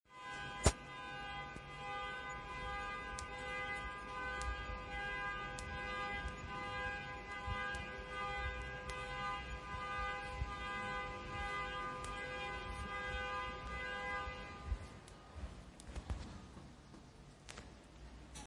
Download Car Alarm sound effect for free.
Car Alarm